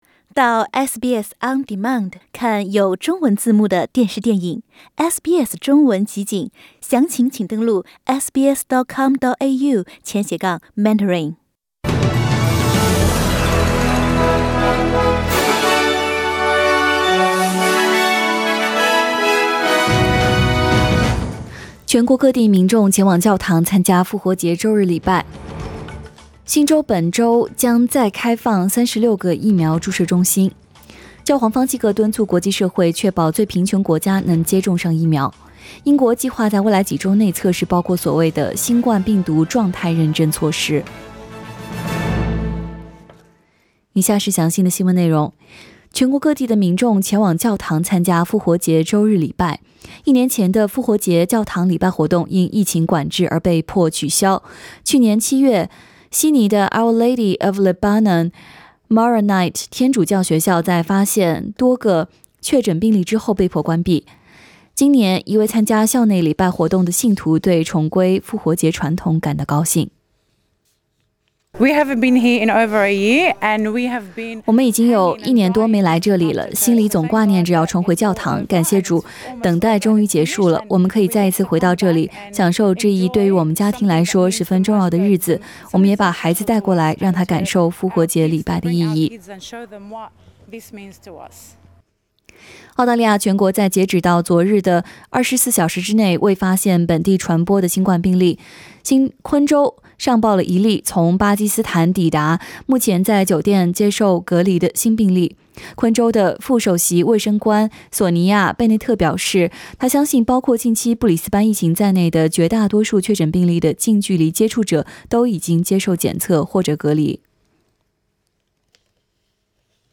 SBS 早新聞 （4月5日）